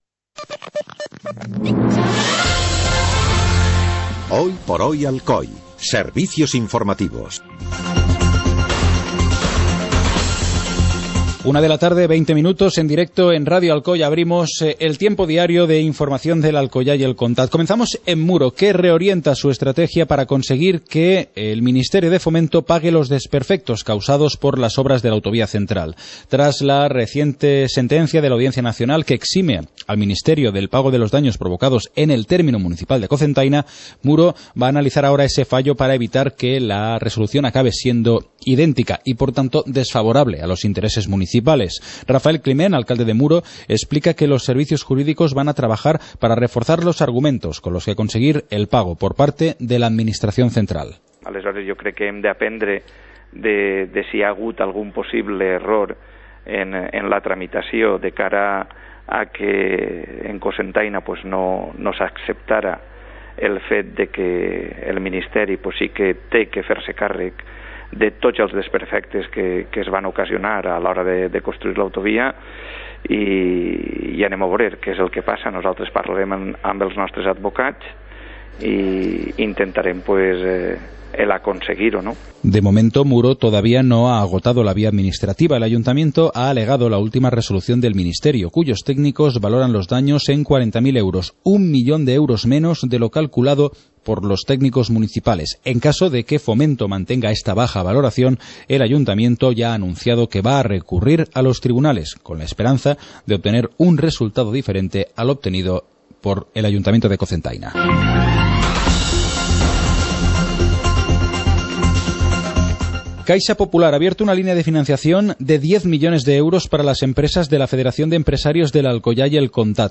Informativo comarcal - martes, 30 de diciembre de 2014